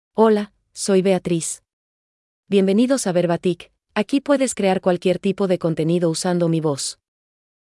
FemaleSpanish (Mexico)
Beatriz — Female Spanish AI voice
Beatriz is a female AI voice for Spanish (Mexico).
Voice sample
Beatriz delivers clear pronunciation with authentic Mexico Spanish intonation, making your content sound professionally produced.